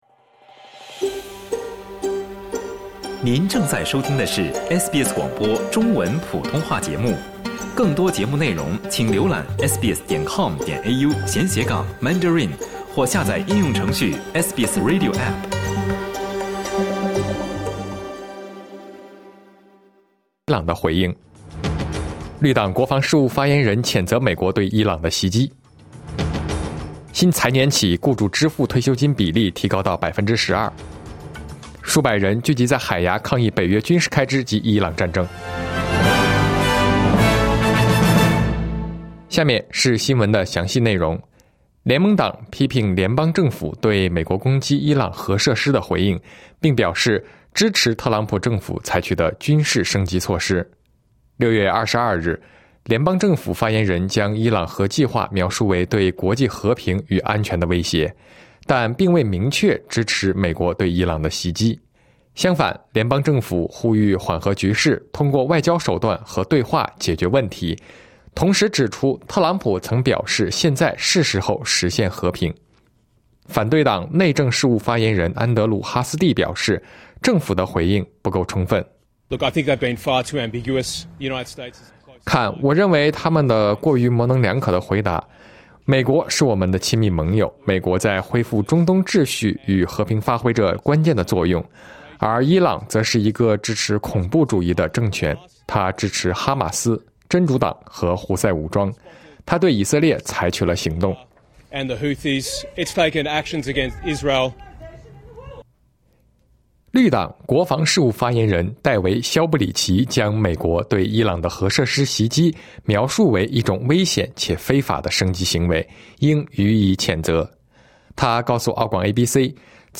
SBS早新闻（2025年6月23日）